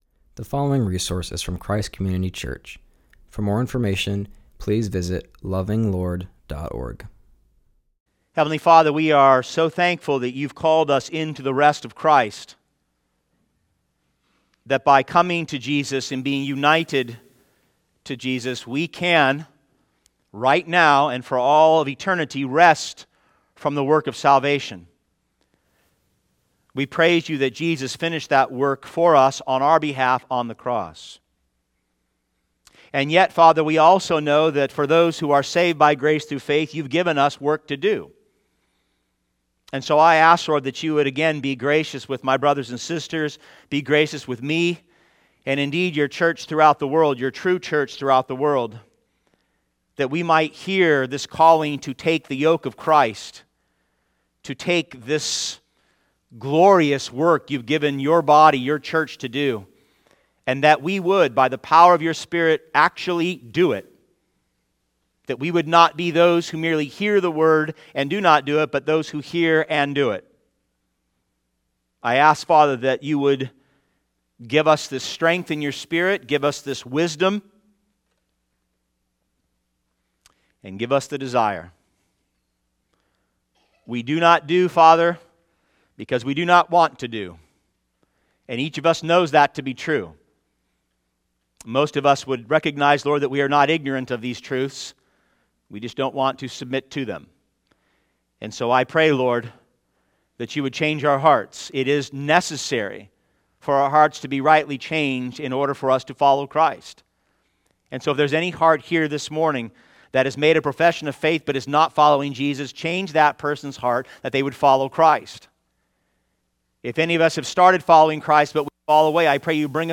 preaching on Matthew 11:28-30